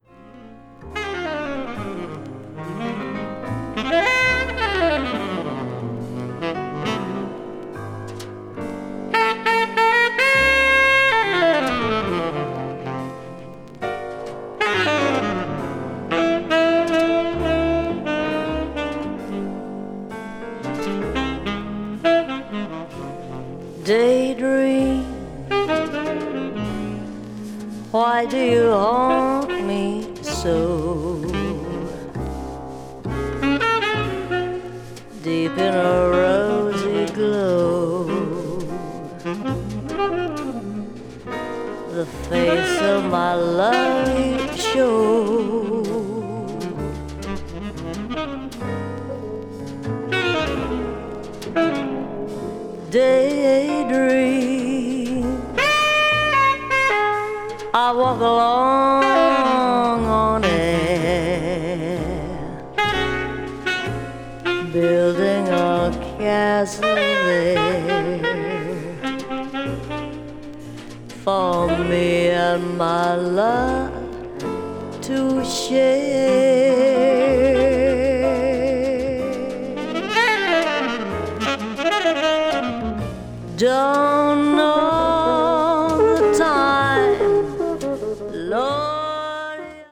contemporary jazz   jazz vocal   modal jazz   modern jazz